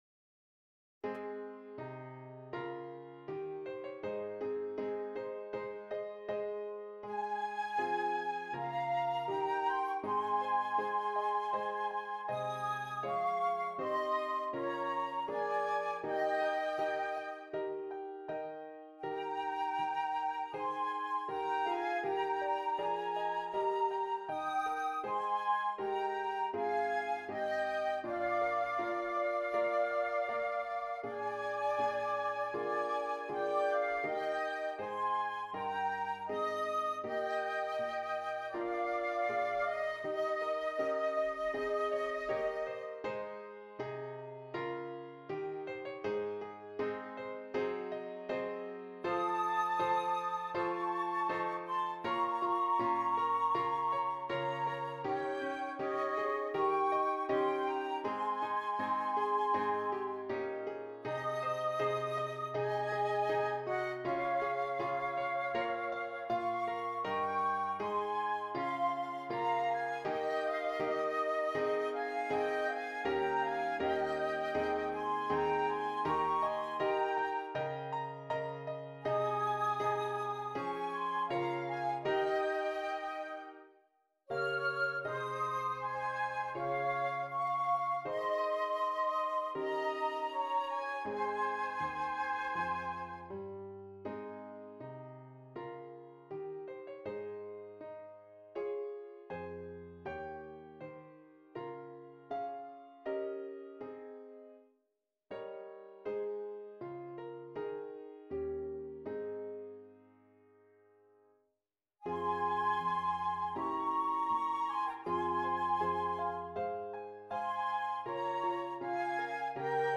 2 Flutes and Keyboard